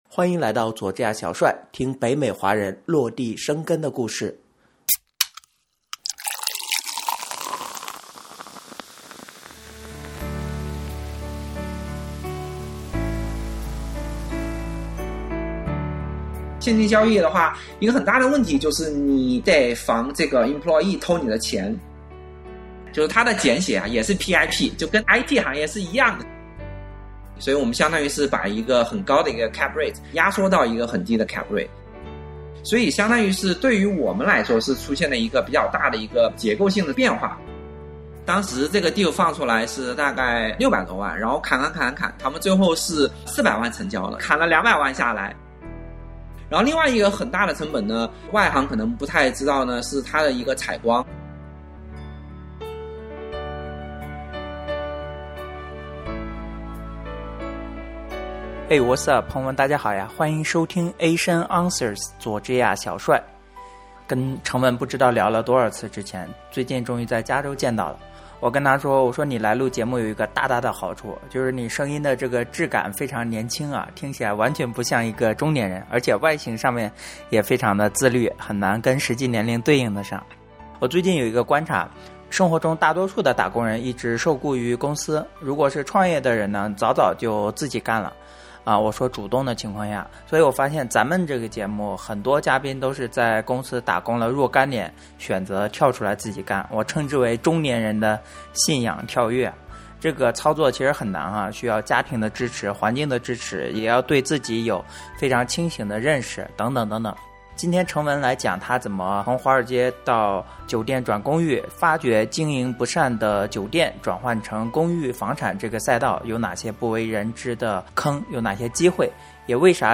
我说你来录节目有一个大大的好处，声音质感非常年轻啊，听起来简直不像一个中年人。